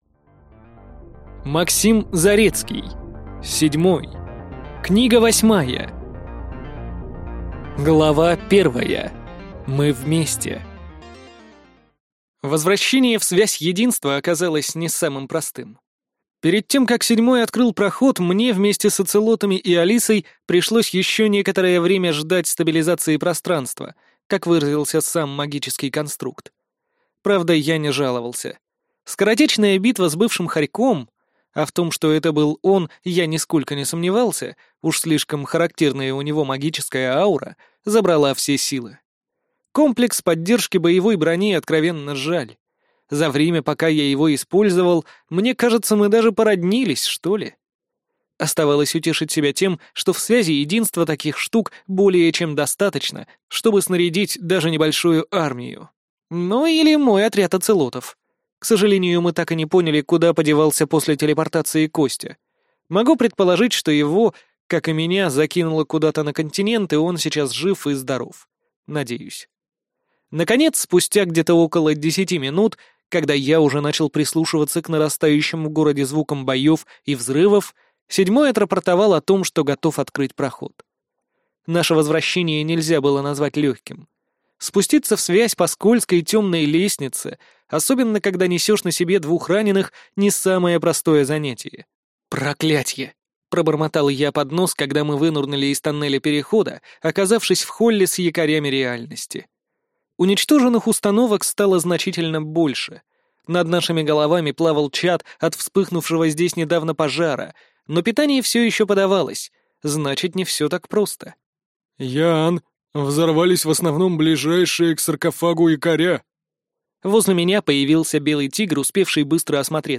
Аудиокнига Седьмой. Книга 8 | Библиотека аудиокниг